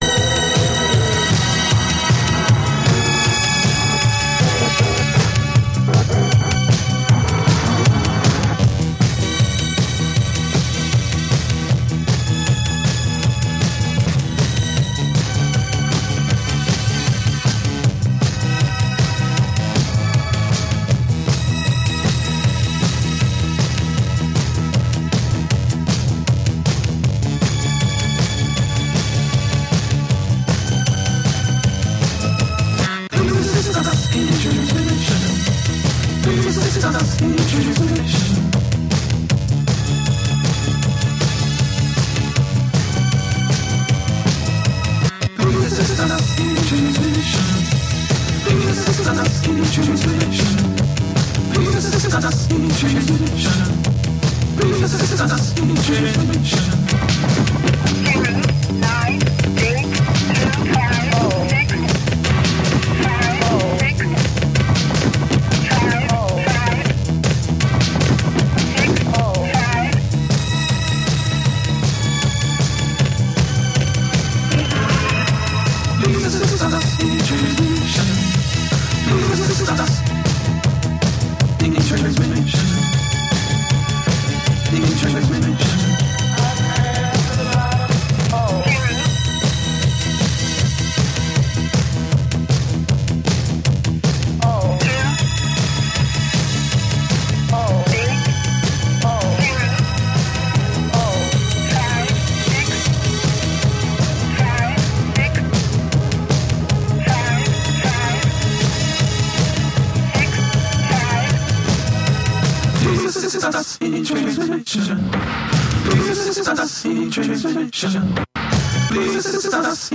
Collection of sound works by the Troy-based artist.